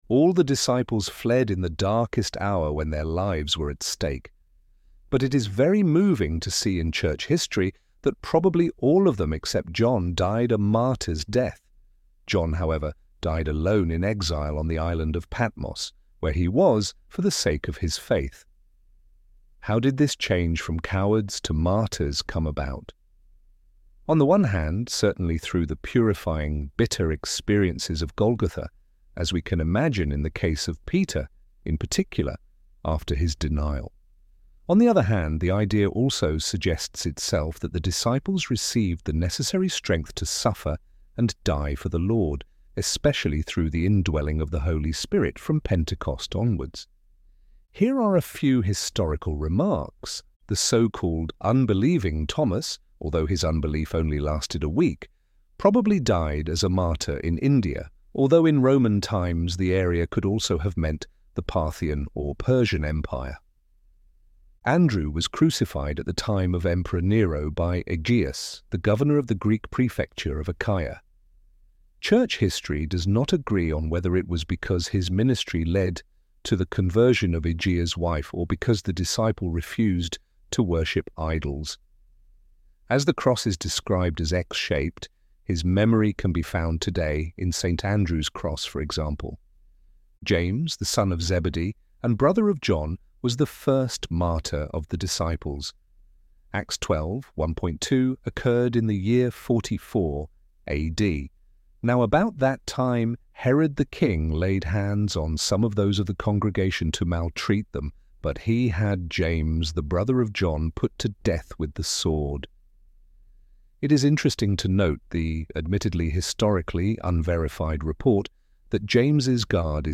ElevenLabs_Cowards_Become_Martyrs.mp3